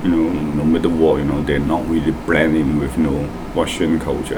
S1 = Hong Kong male S2 = Malaysian female Context: S1 is talking about the situation in Bulgaria. S1 : ... you know in no matter what they not really blend in with you know (.) russian culture Intended Words : no matter what Heard as : the middle war Discussion : The word matter has a flapped /t/, as is the norm in the USA where S1 spent many years. As a result, it sounds like [d].
One further problem seems to be the insertion of in before no matter what , though it is spoken quite fast, so it is hard to be sure.